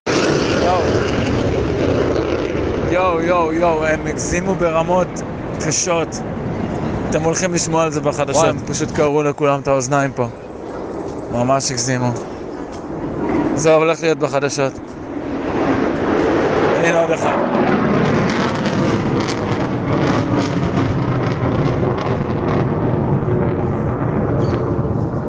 האזינו: רעש מטוסים החריד את תל אביב
לידי תל אביב אונליין הגיעו קטעי קול ווידאו של הרעש המחריד, המדברים בעד עצמם.
airplane.mp3